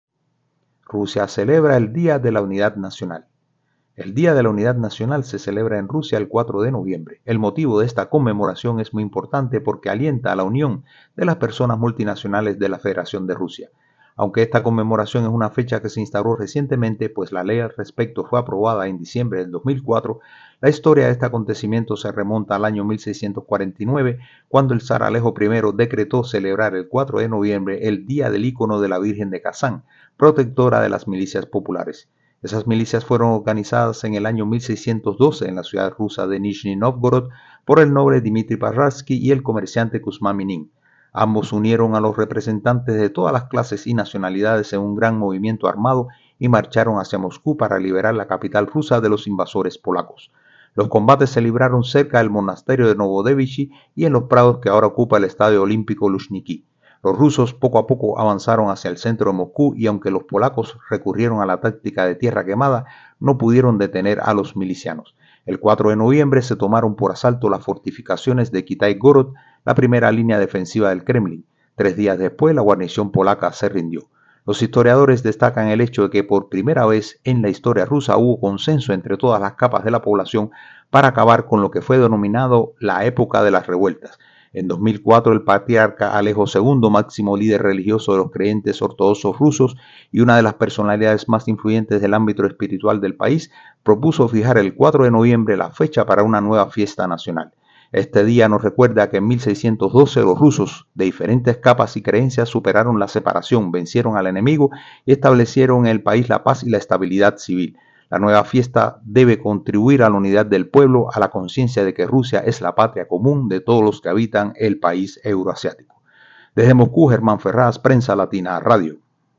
desde Moscú